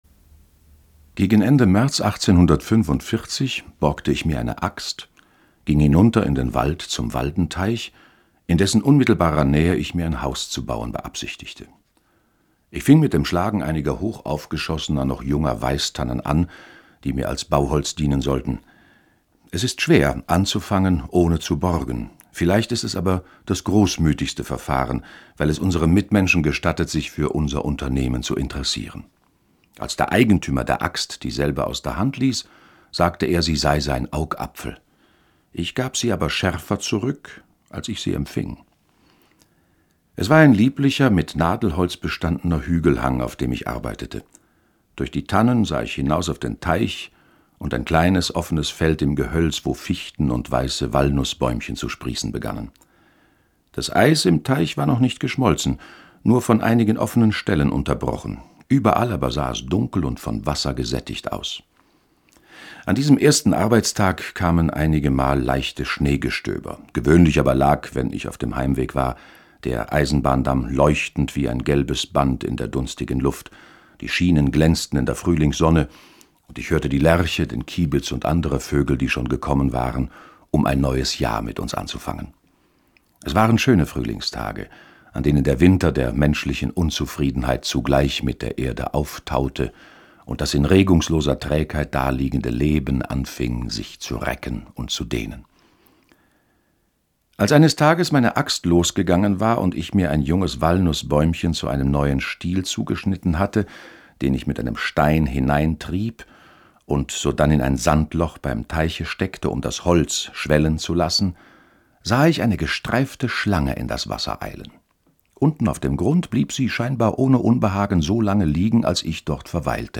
Henry David Thoreau: Walden oder Leben in den Wäldern (2/11) ~ Lesungen Podcast